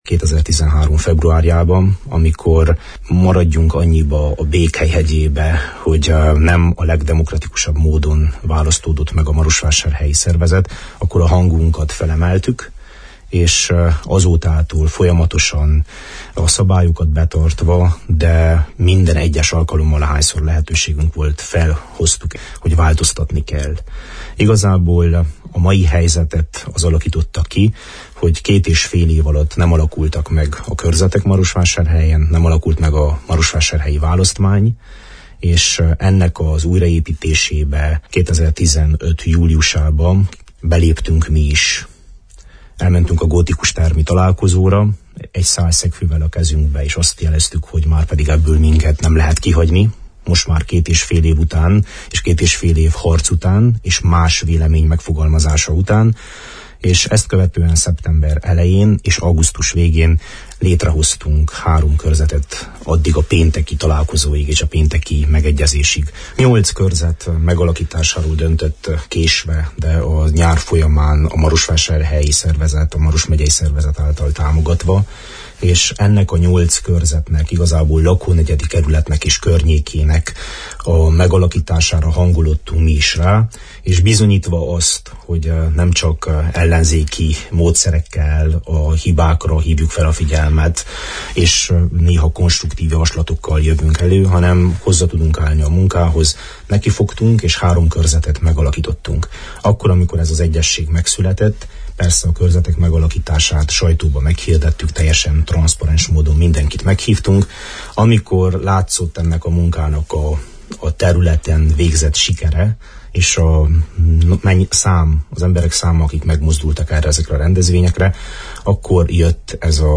A megbékélés előzményeiről, a további közös munka jelentőségéről nyilatkozott Dr. Vass Levente a szerda délutáni Naprakész műsor vendégeként.